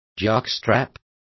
Complete with pronunciation of the translation of jockstraps.